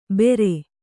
♪ bere